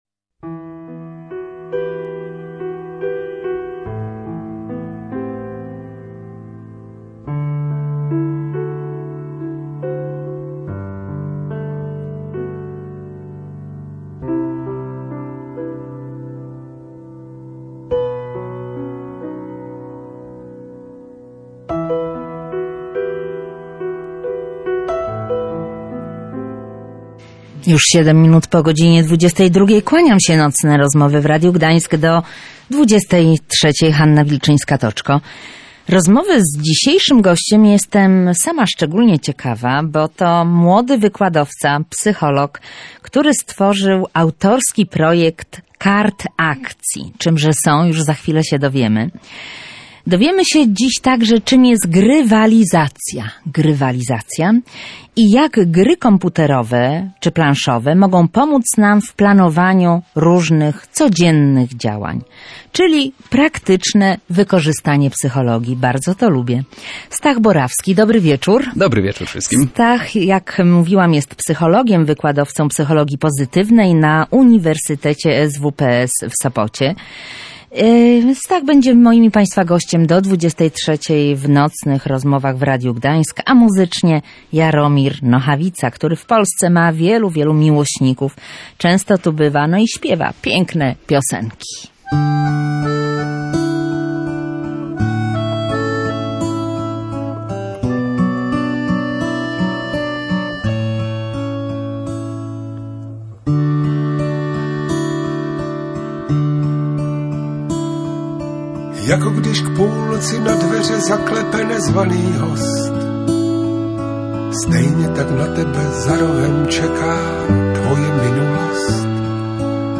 archiwum audycji Rozmowy Nocą